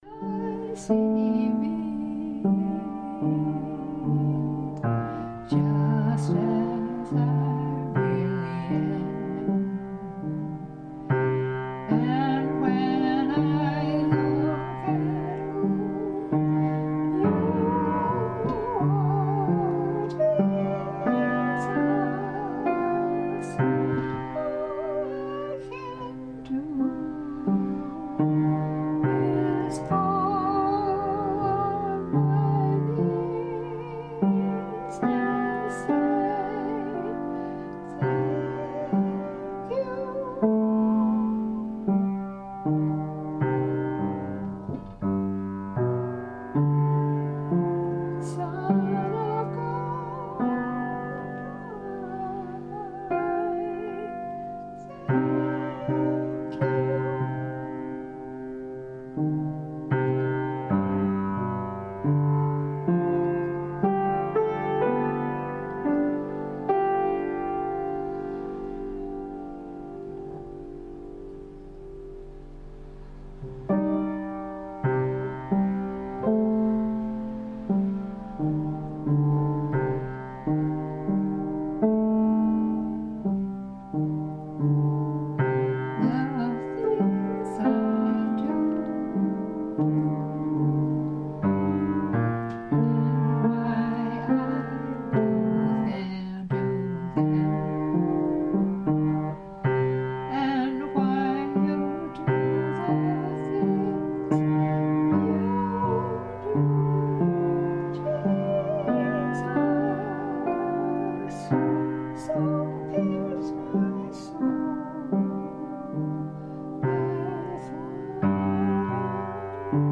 Sung and played from memory at age 88 in early or middle stage of Alzheimer’s: